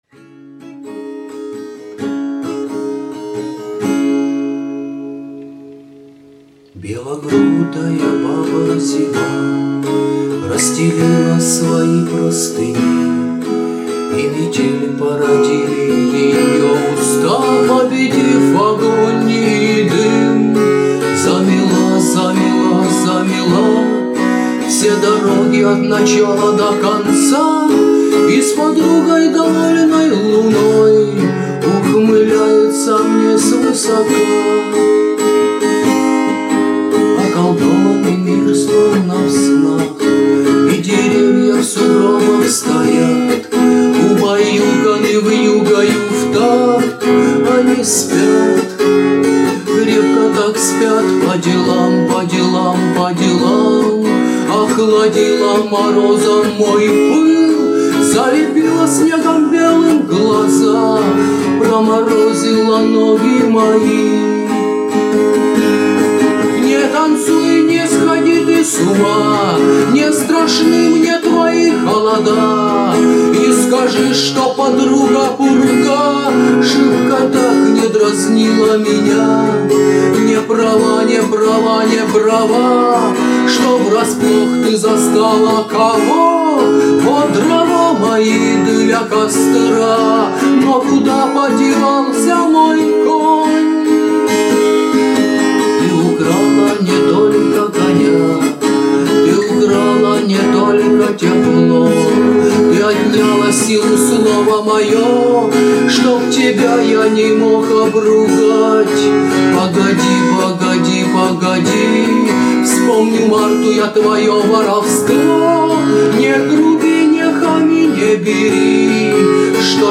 Авторская песня в исполнени автора